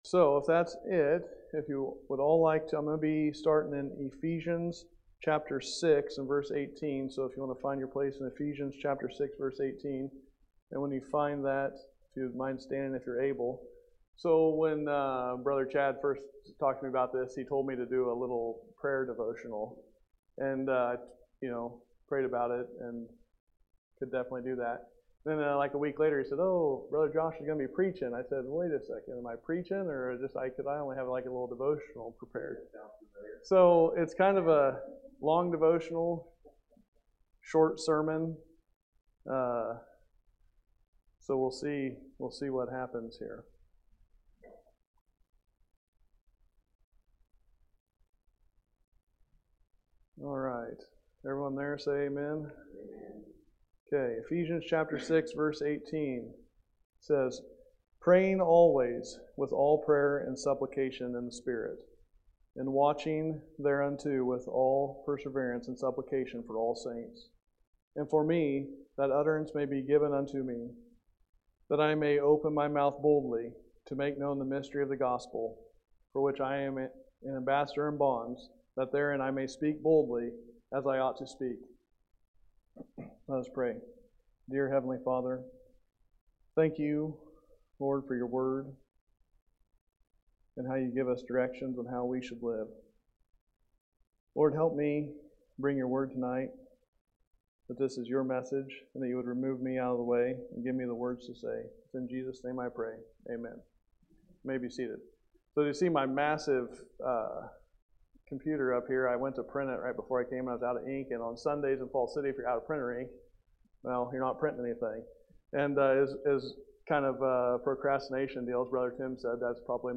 Ephesians 6:18 – Camp Corporate Prayer Meeting